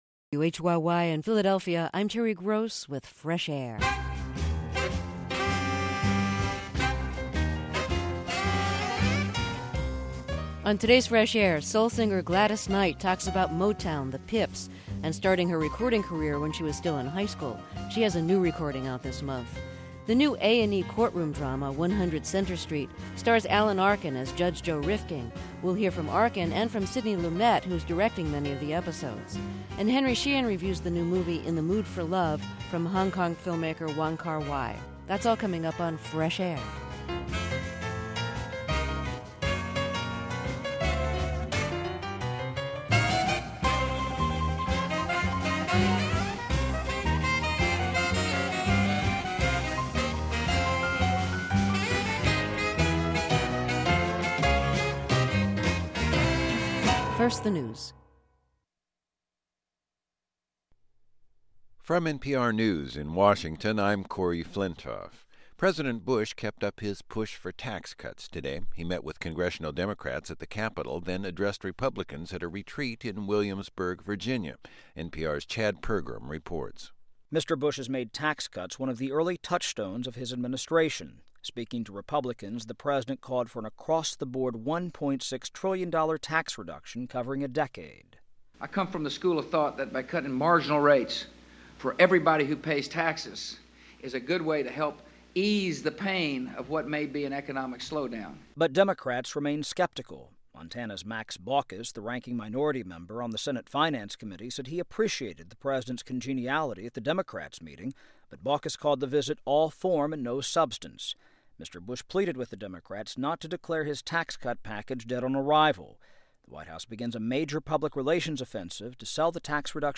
Terry interviewed Knight before a live audience in San Francisco in 1996.